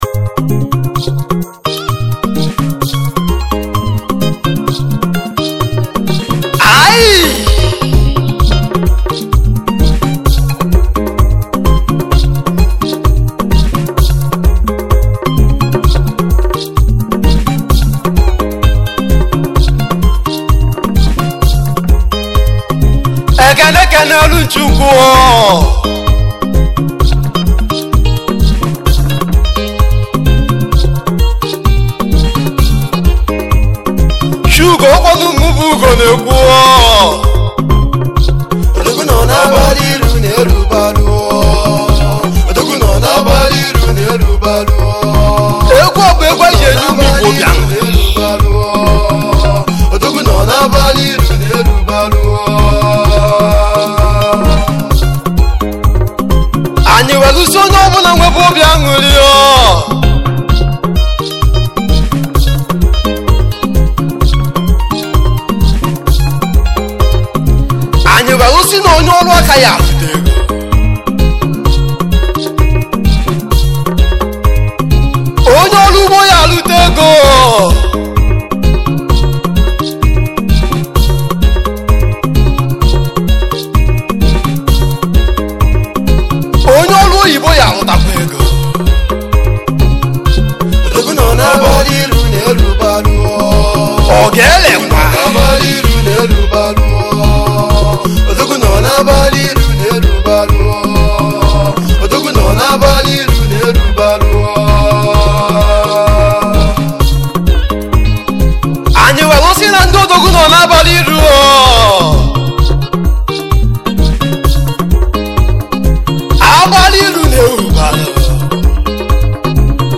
igbo highlife